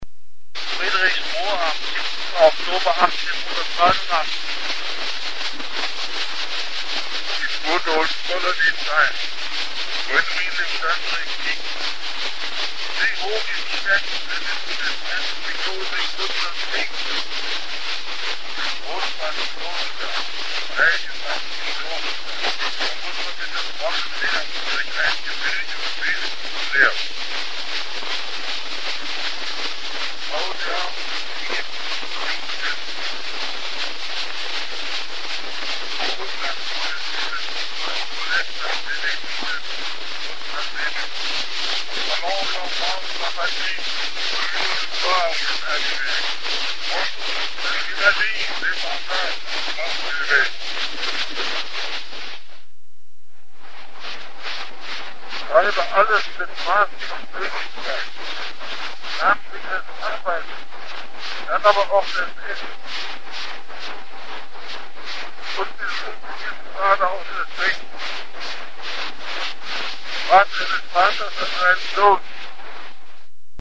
Otto von Bismarck auf Edison-Zylinder 1889